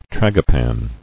[trag·o·pan]